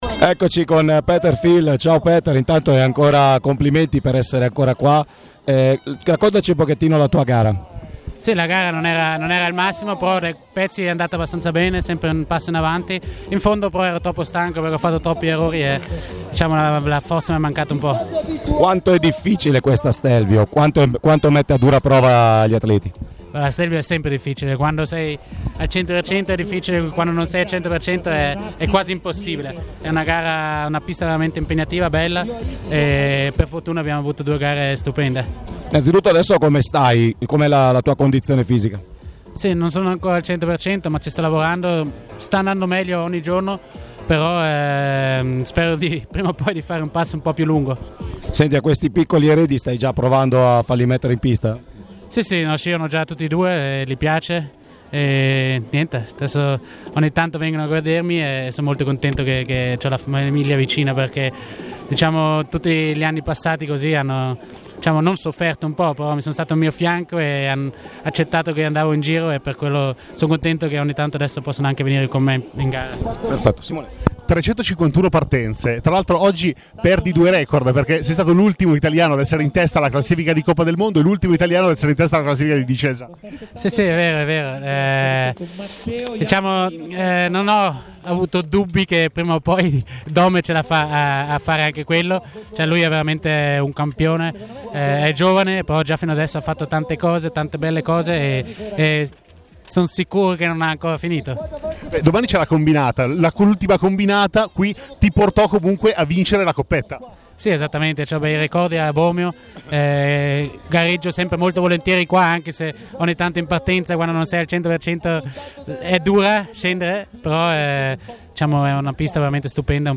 Intervista audio con Peter Fill: